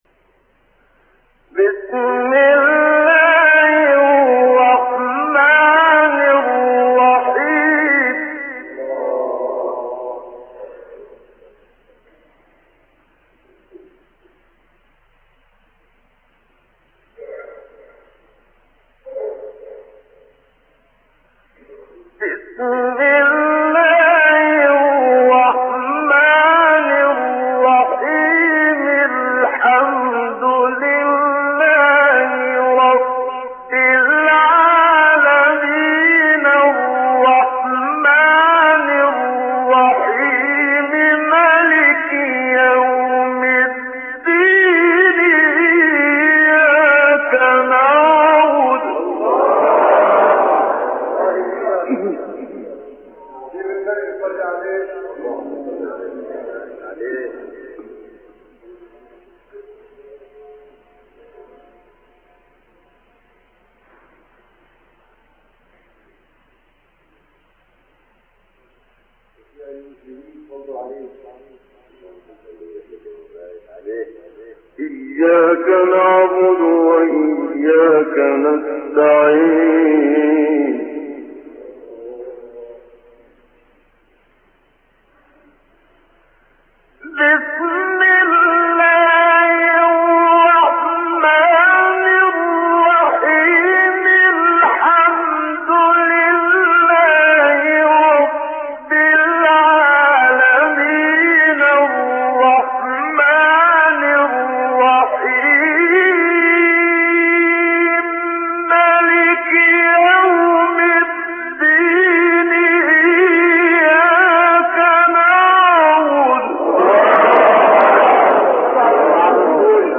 تلاوت سوره حمد و آیات ابتدایی سوره بقره استاد منشاوی | نغمات قرآن | دانلود تلاوت قرآن